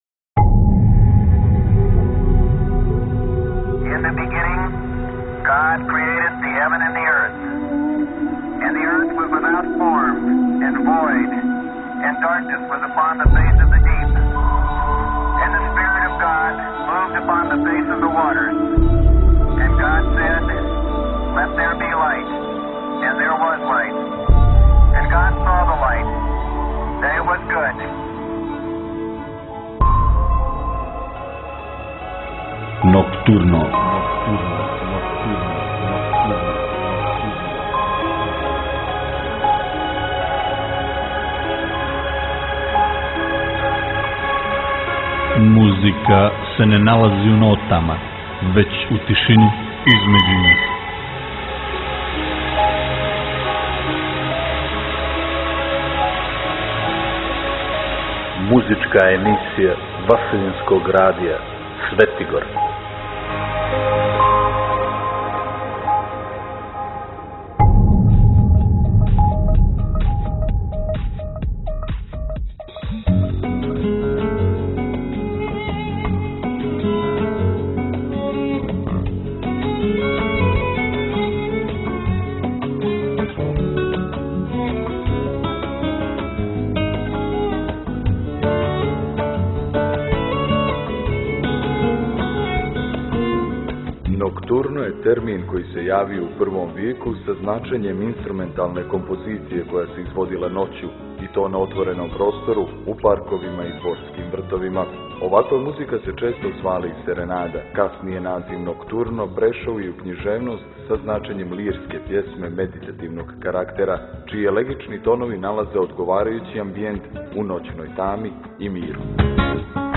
flamenko virtuoze